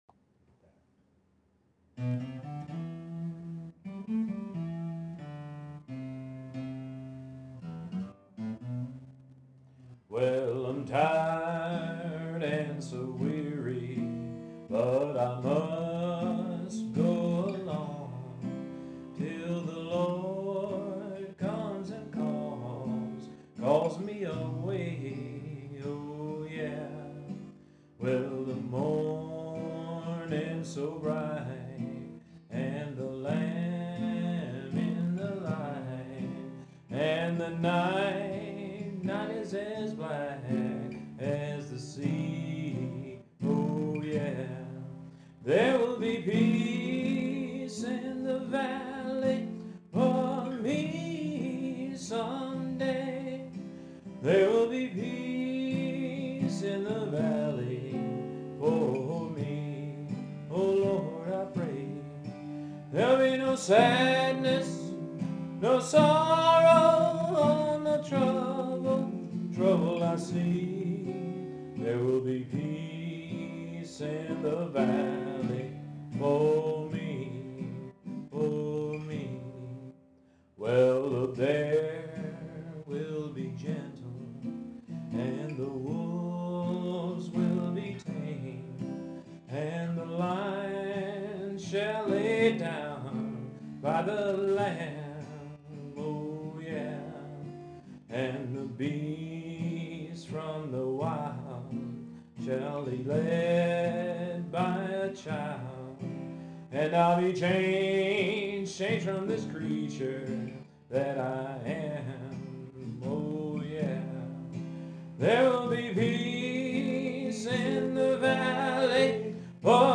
Special Music
Written By:  Thomas A. Dorsey